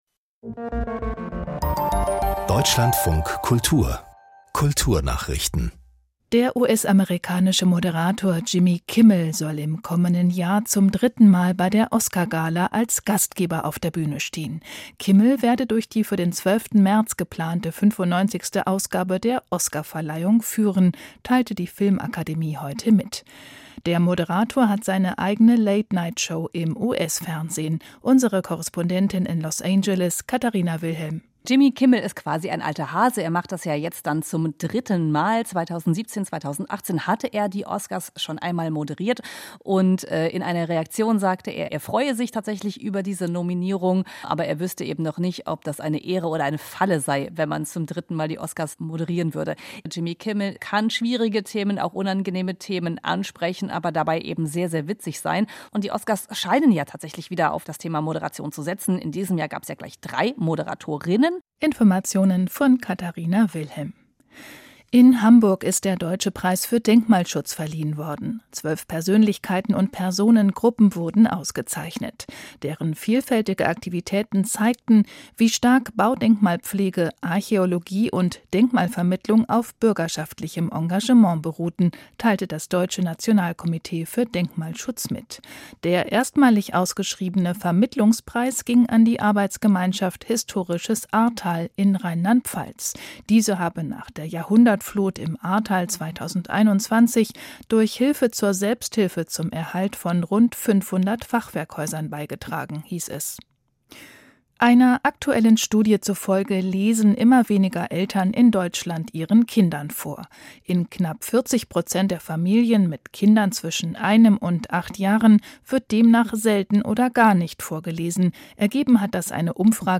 Kulturnachrichten - 07.11.2022